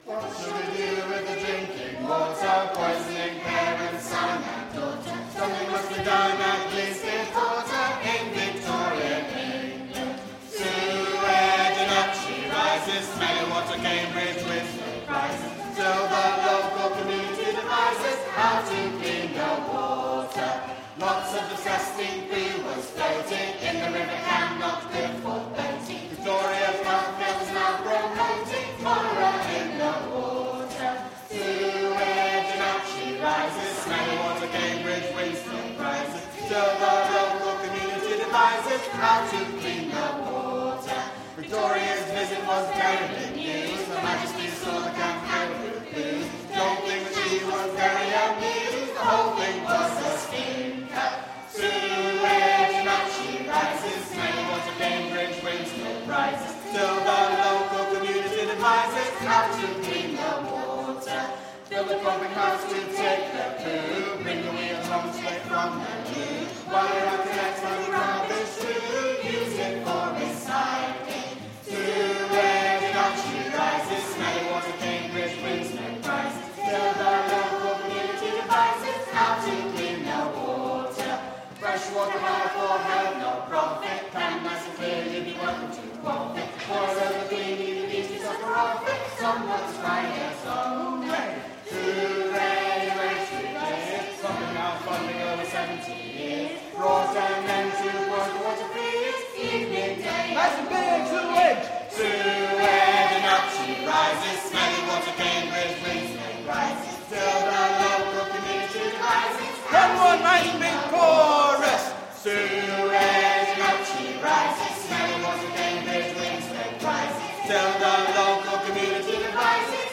Singing History Concert 2016: What Shall We Do With the Drinking Water 1